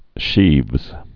(shēvz)